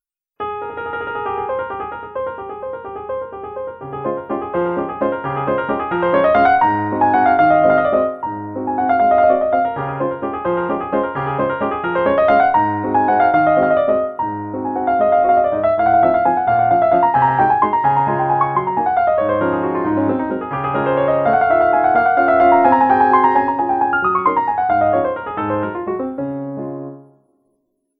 ピアノ環境を整備した。
マスタリングを少し触って艶っぽくしてみた。